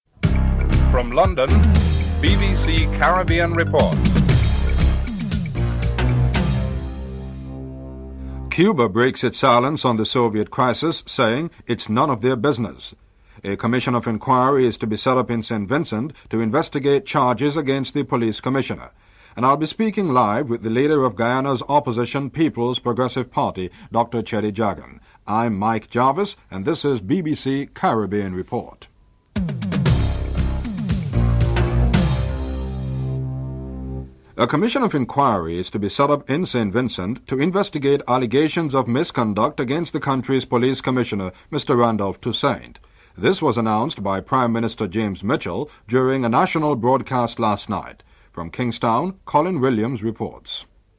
1. Headlines (00:00-00:33)